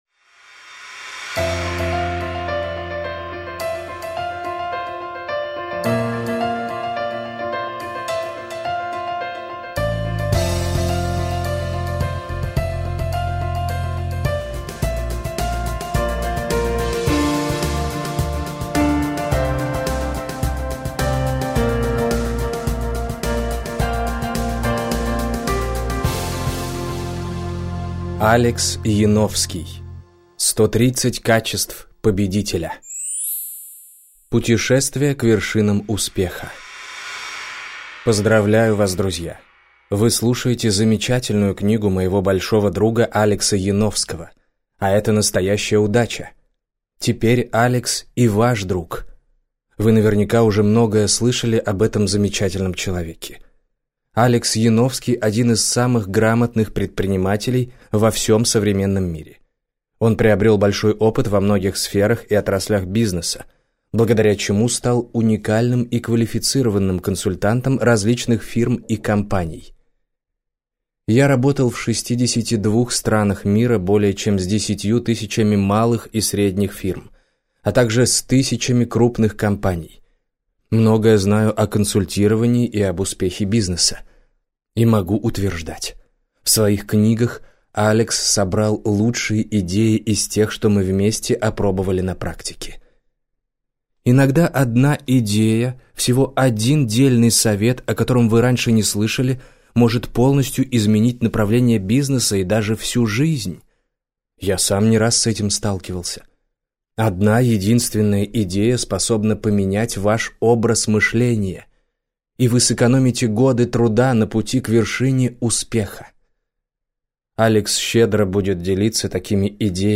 Аудиокнига 130 качеств победителя - купить, скачать и слушать онлайн | КнигоПоиск
Прослушать фрагмент аудиокниги 130 качеств победителя Алекс Яновский Произведений: 1 Скачать бесплатно книгу Скачать в MP3 Вы скачиваете фрагмент книги, предоставленный издательством